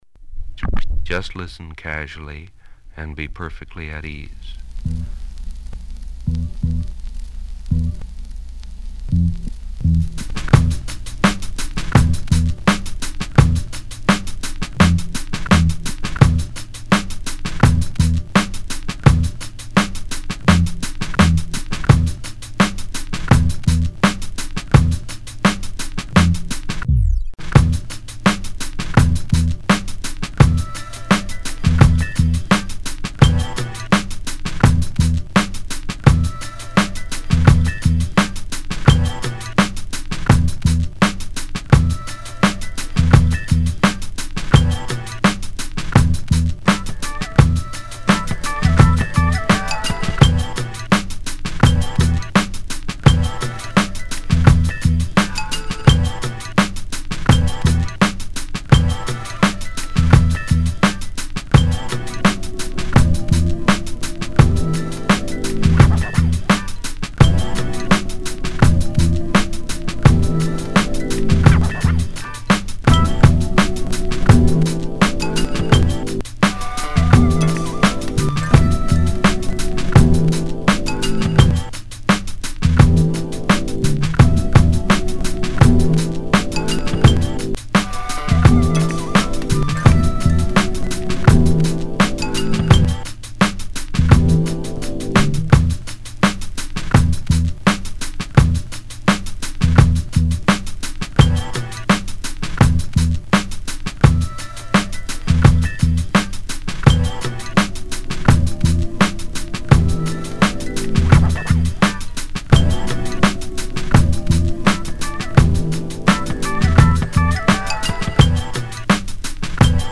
I made it with a Boss SP-202 and a program called Internet Audio Mix.